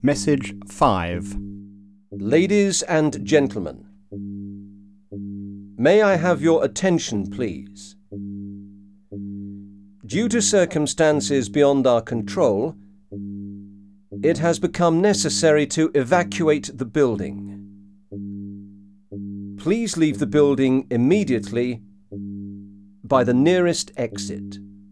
Evacuation Message: 5 (Male)